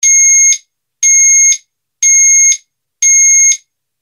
SIRENA 8 TONOS
Sirena con 8 tonos diferentes
Tono_5